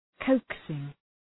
Προφορά
{‘kəʋksıŋ}